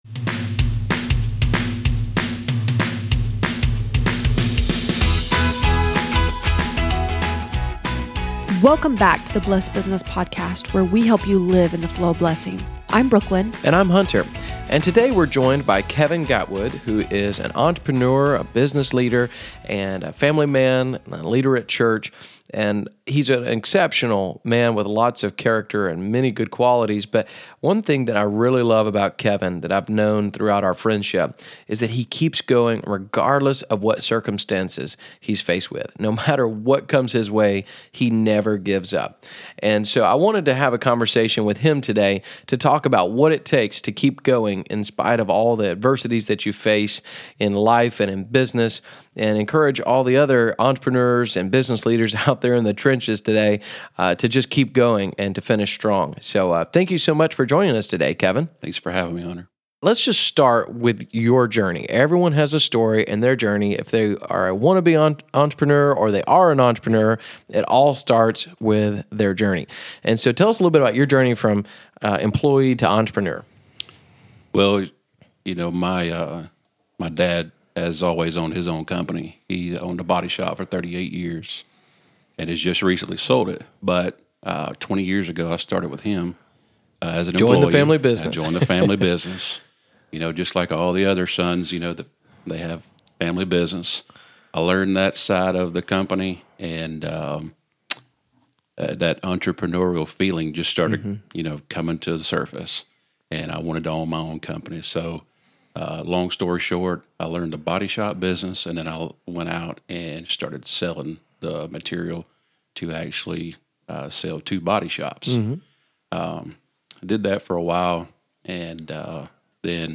In this interview, he shares some of the things that keeps him going when the going gets tough.